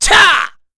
Ezekiel-Vox_Attack3_kr.wav